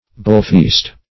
\Bull"feast`\